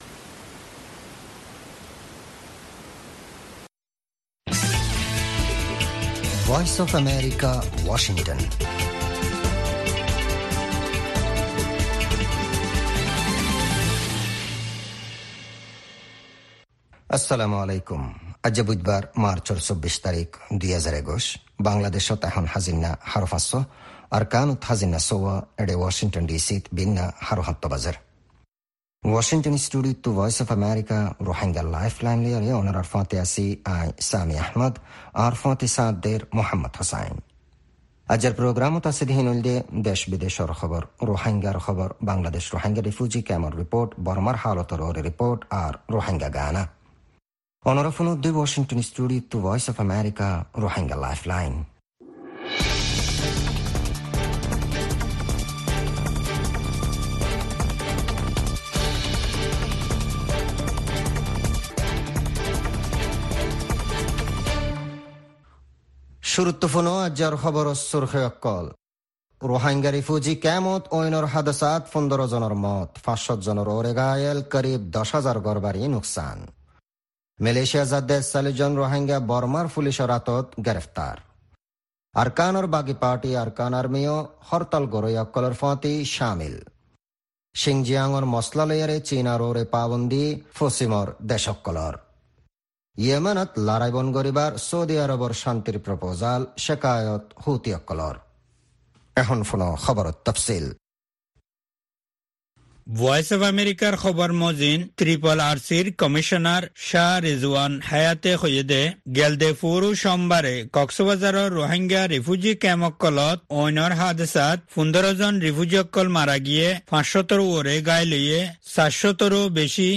Embed Rohingya Broadcast Embed The code has been copied to your clipboard.